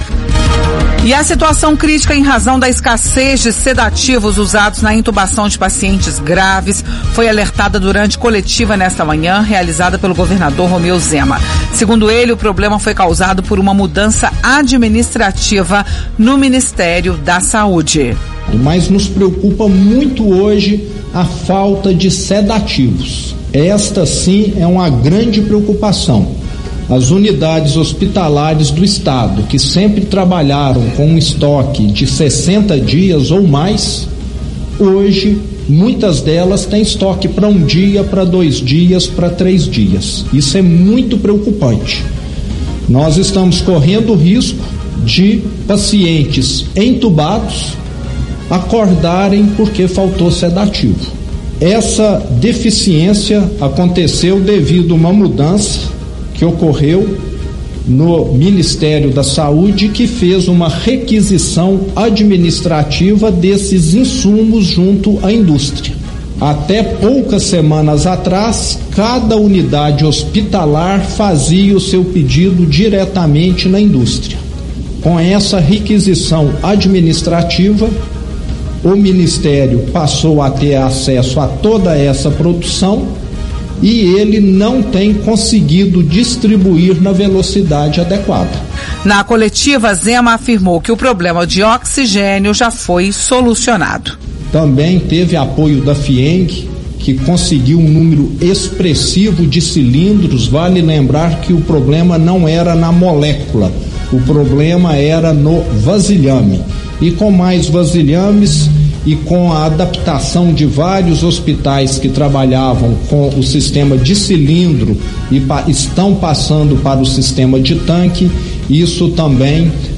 A situação crítica em razão da escassez de sedativos usados na intubação de pacientes graves foi alertada durante coletiva nesta quinta-feira, 8, realizada pelo governador de Minas Gerais, Romeu Zema. Segundo ele, o problema foi causado por uma mudança administrativa no Ministério da Saúde.